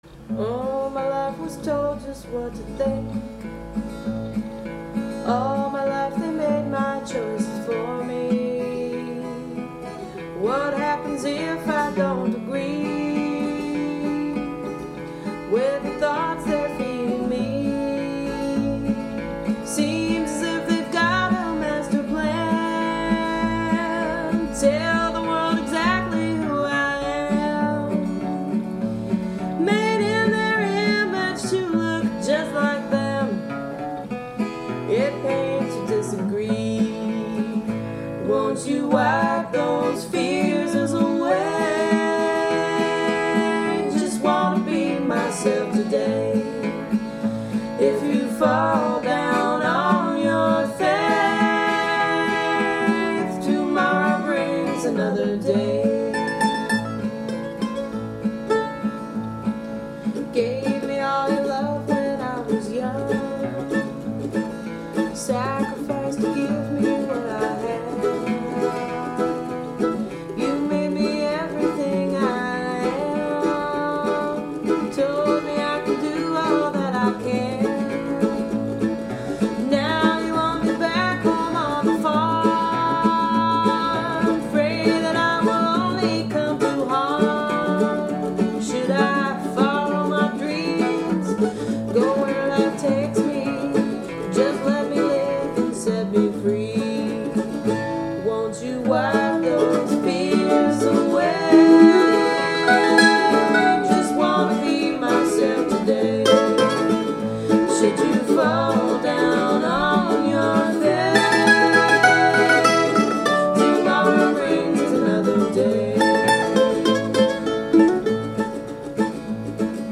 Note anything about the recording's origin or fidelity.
MP3 from ‘roots’ demo